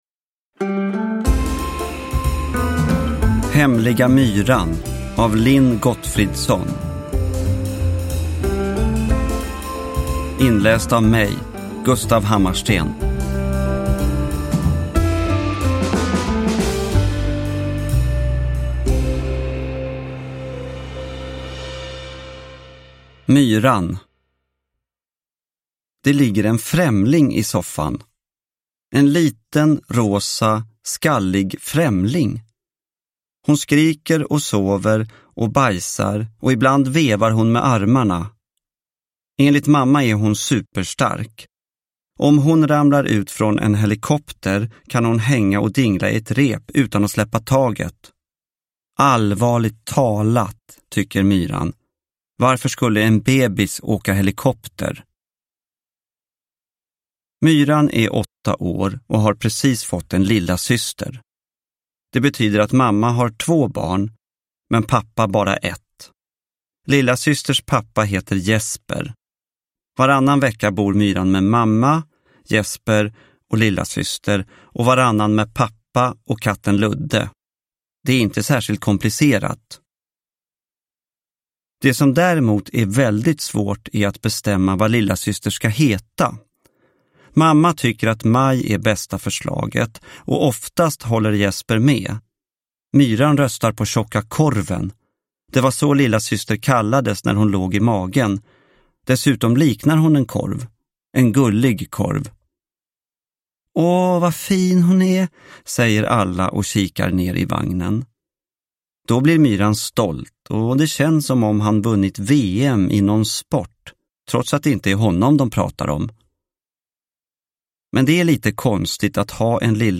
Hemliga Myran – Ljudbok – Laddas ner
Uppläsare: Gustaf Hammarsten